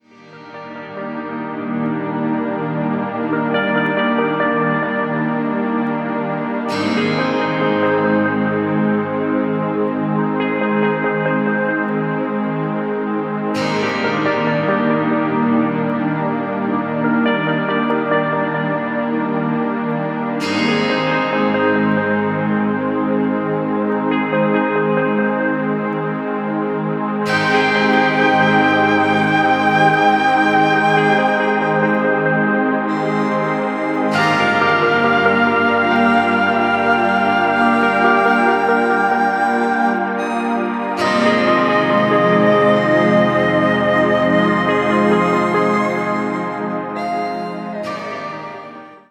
Irgendwie asiatisch, mystisch und nicht von dieser Welt.
Eine besondere Art der spirituellen Entspannungsmusik …